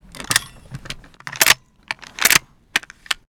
machinegun_reload_01.wav